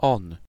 Ääntäminen
Vaihtoehtoiset kirjoitusmuodot hey hay (vanhentunut) hee (rikkinäinen englanti) 'e hei Synonyymit they it he or she (muodollinen) s/he Ääntäminen : IPA : /hi/ US : IPA : [hi] UK Tuntematon aksentti: IPA : /ˈhiː/ IPA : /hi/, /i/ IPA : /heɪː/